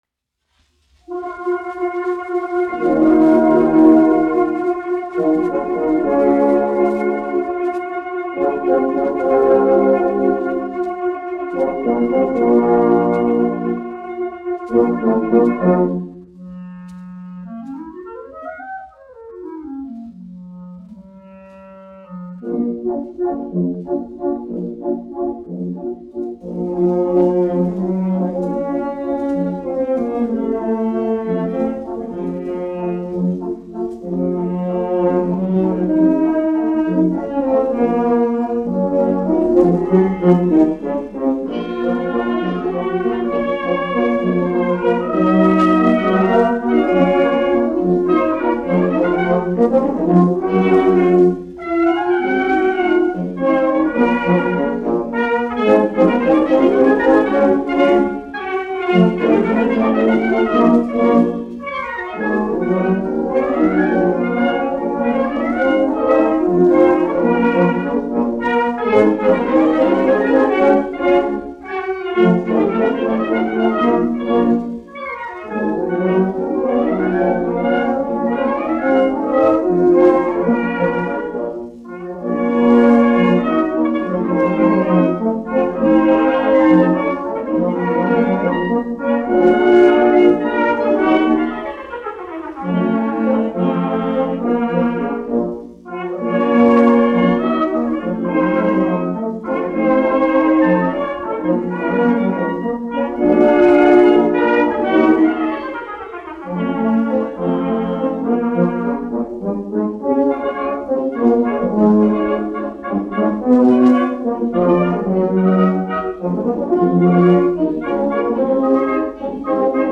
Latvijas 4. Valmieras kājnieku pulka orķestris, izpildītājs
1 skpl. : analogs, 78 apgr/min, mono ; 25 cm
Valši
Pūtēju orķestra mūzika
Skaņuplate